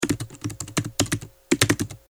キーボード | 無料 BGM・効果音のフリー音源素材 | Springin’ Sound Stock
タイピング-パンタグラフ短3.mp3